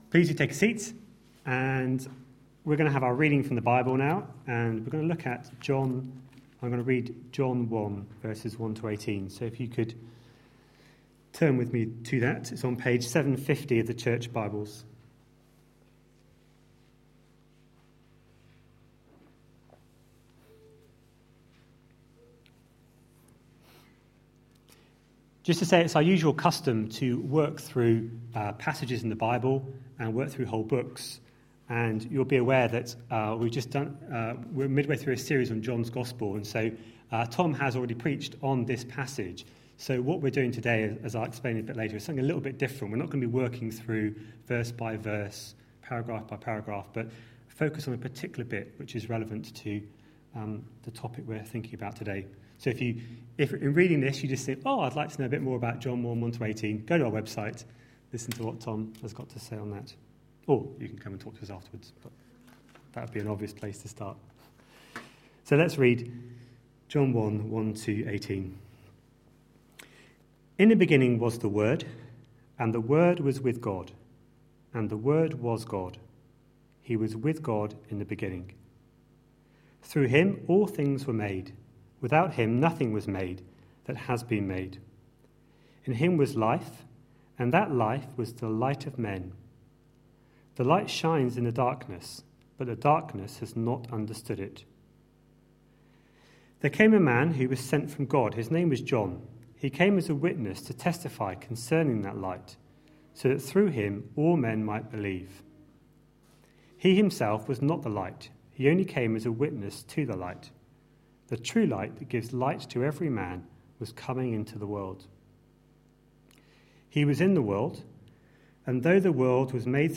A sermon preached on 15th December, 2013.